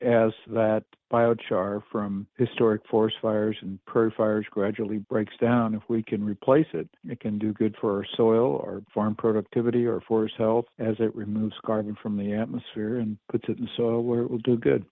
Broadcast version